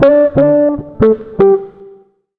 160JAZZ  2.wav